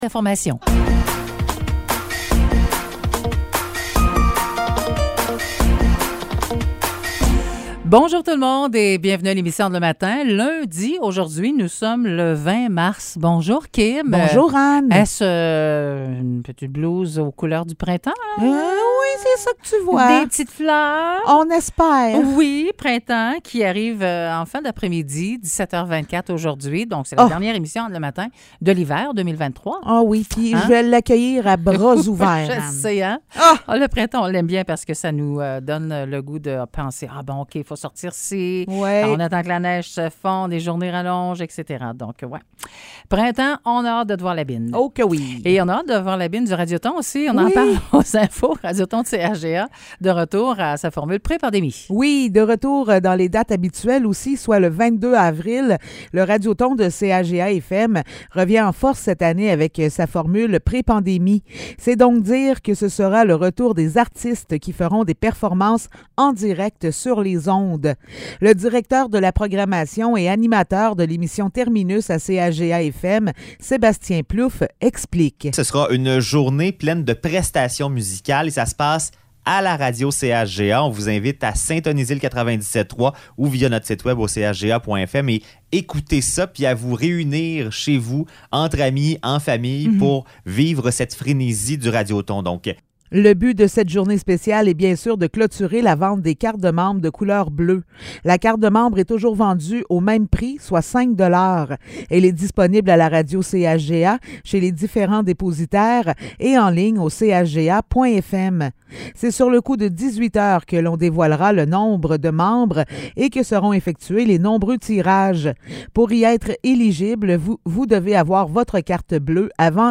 Nouvelles locales - 20 mars 2023 - 9 h